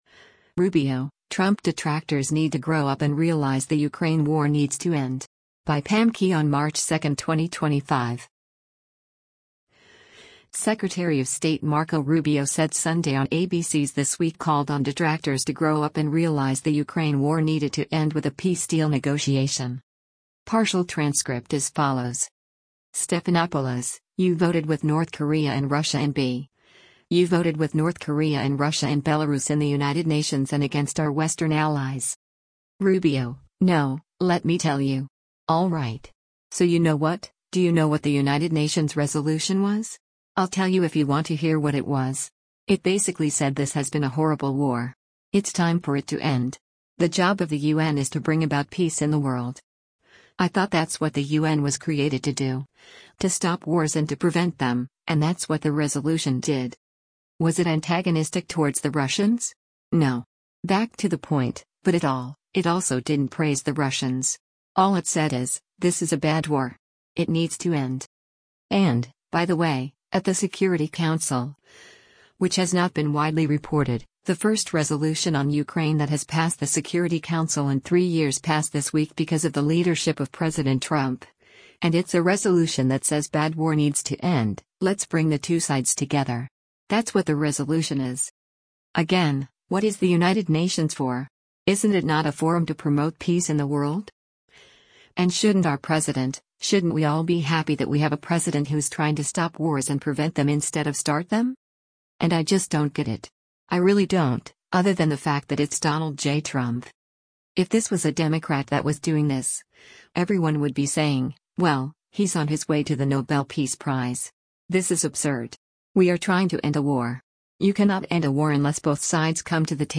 Secretary of State Marco Rubio said Sunday on ABC’s “This Week” called on detractors to “grow up and realize” the Ukraine war needed to end with a peace deal negotiation.